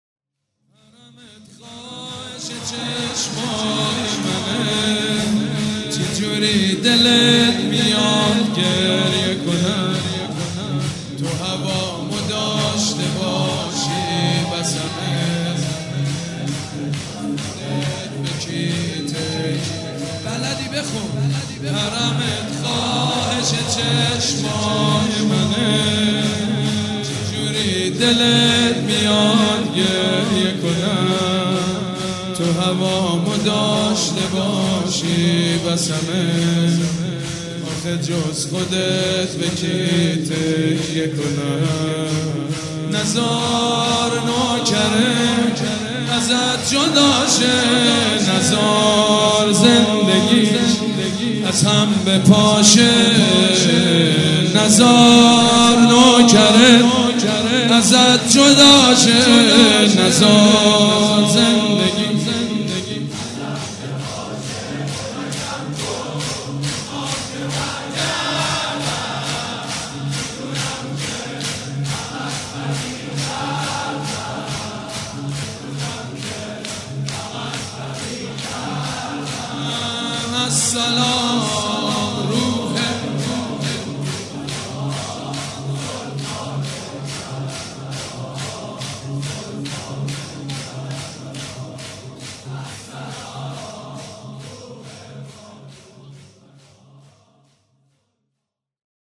سبک اثــر شور مداح حاج سید مجید بنی فاطمه
مراسم عزاداری شب پنجم